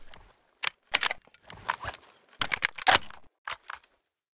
sa58_reload.ogg